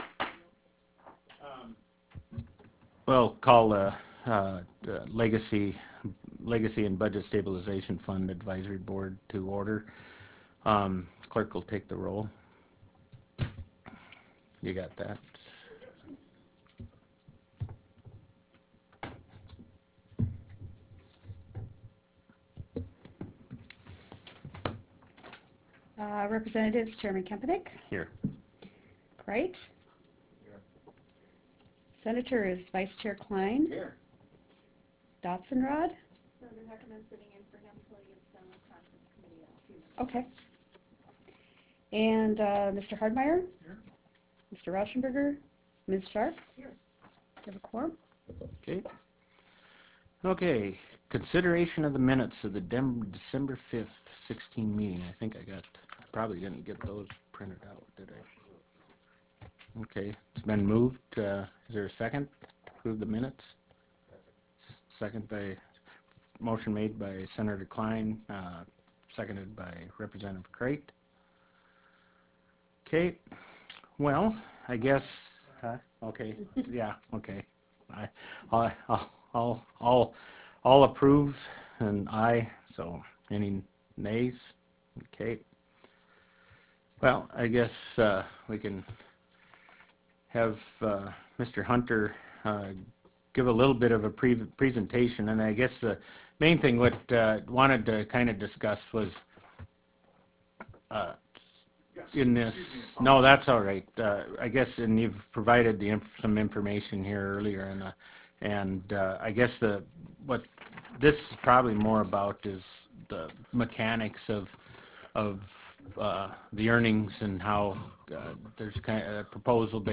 Medora Room State Capitol Bismarck, ND United States